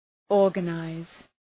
Προφορά
{‘ɔ:rgə,naız}
organize.mp3